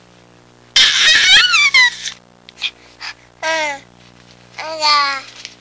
Happy Baby Sound Effect
Download a high-quality happy baby sound effect.
happy-baby.mp3